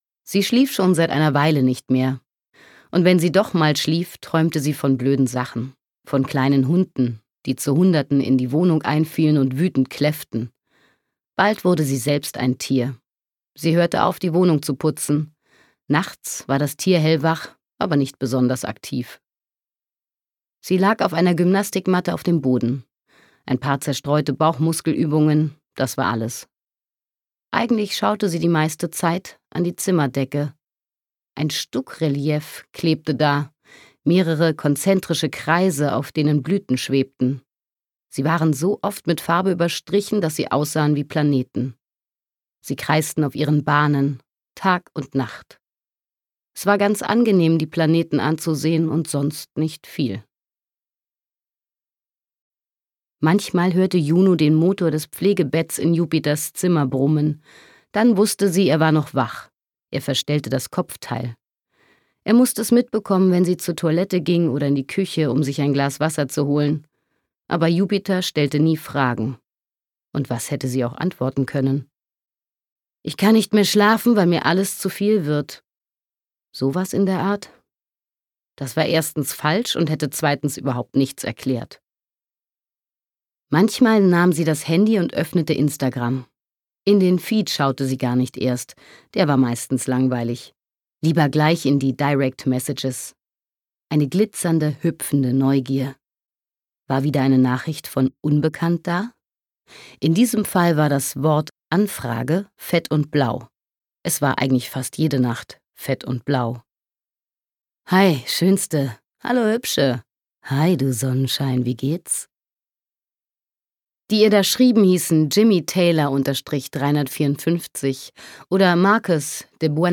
2024 | 1. Auflage, Ungekürzte Ausgabe